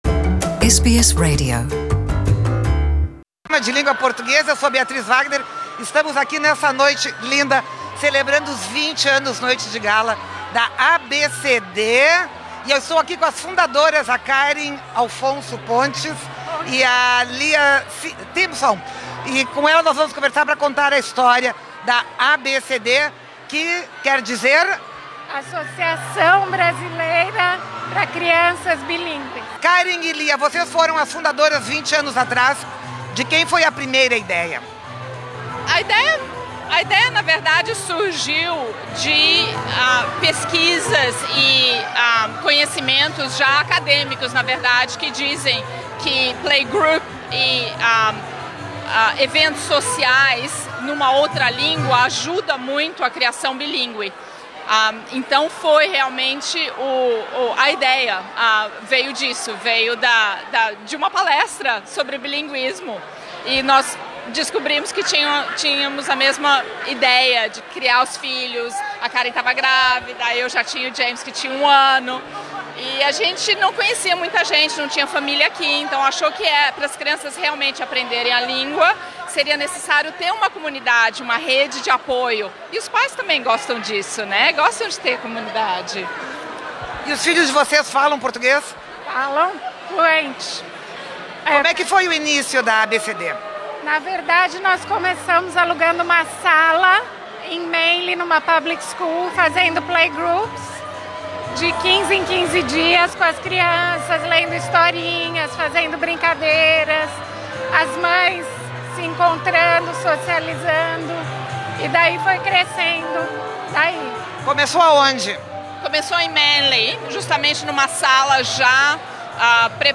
A Associação Brasileira para o Desenvolvimento da Criança Bilíngüe (ABCD) celebrou 20 anos de fundação em grande estilo. A Noite de Gala reuniu professores, pais, alunos e ex-alunos na elegante Casa de Chá do Queen Victoria Building, em Sydney. A SBS em Português esteve lá e conferiu a grande festa que terminou em samba.